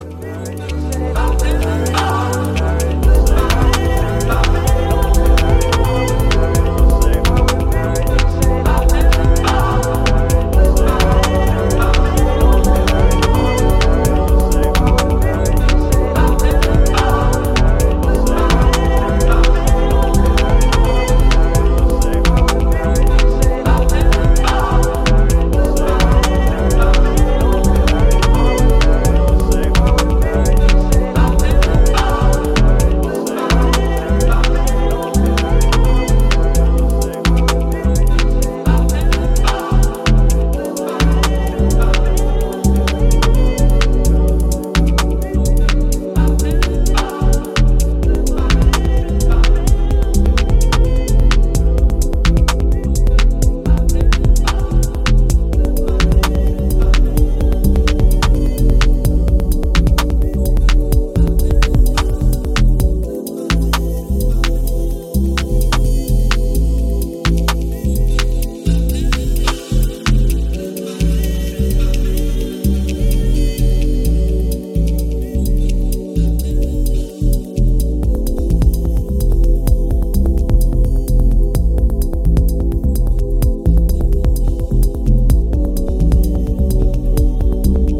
アブストラクトなグルーヴにヴォイス・サンプルが舞うアトモスフェリックでミステリアスな